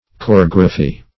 Choregraphy \Cho*reg"ra*phy\, n. [Gr. ? dance + -graphy.]